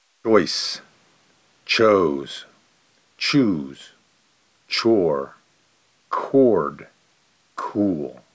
Vowel-shifts_choice.mp3